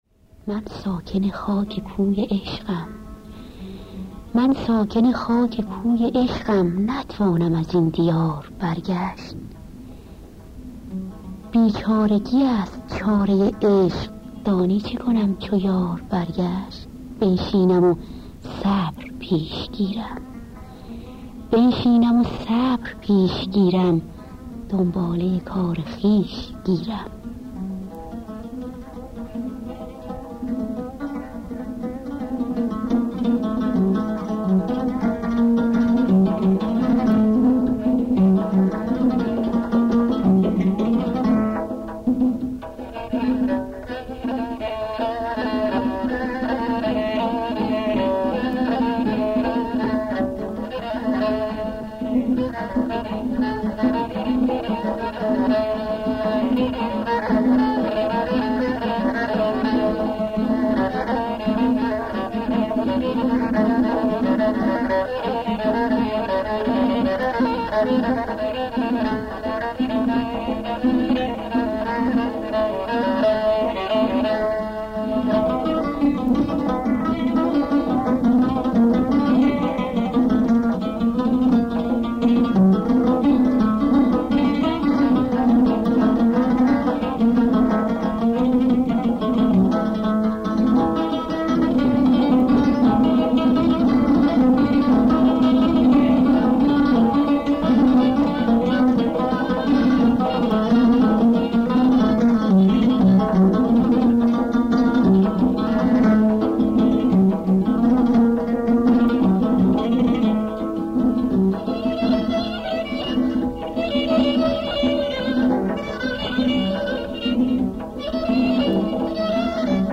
آواز و عود